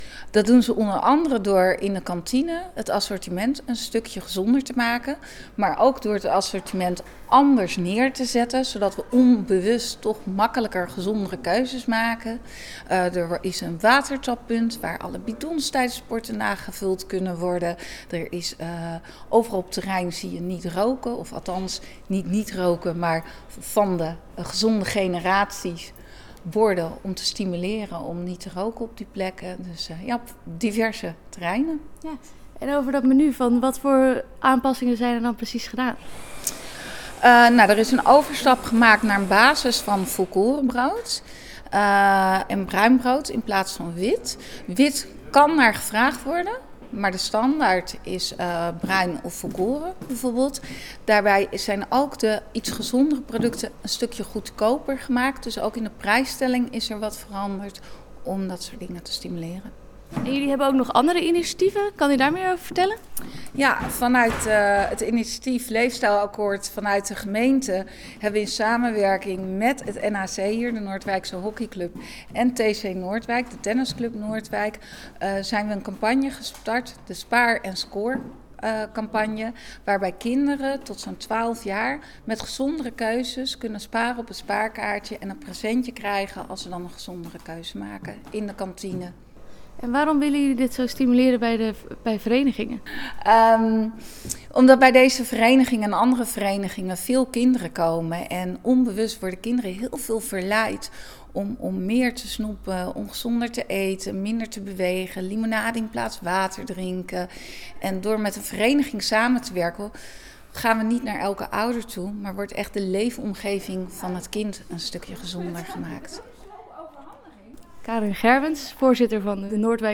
Audioreportage